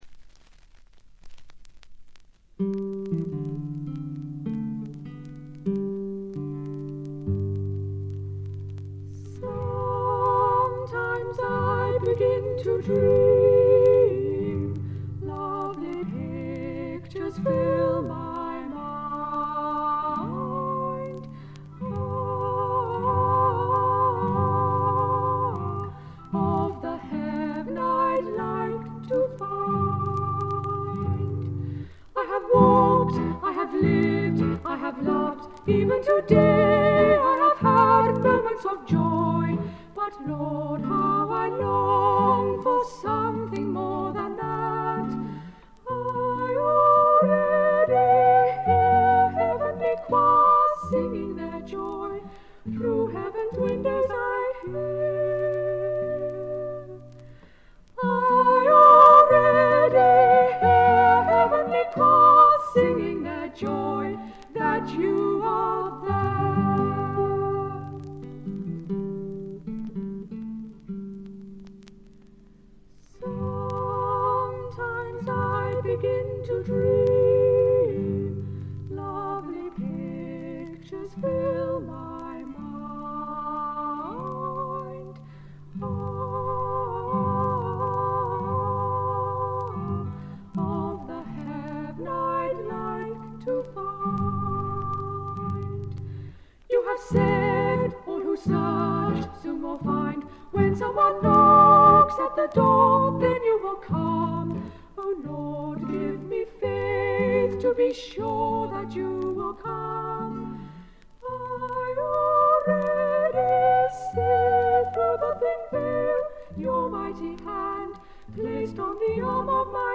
細かなバックグラウンドノイズ、チリプチは出ていますが鑑賞を妨げるようなノイズはありません。
本人たちのオートハープとギターのみをバックに歌われる美しい歌の数々。純粋で清澄な歌声にやられてしまいますよ。
試聴曲は現品からの取り込み音源です。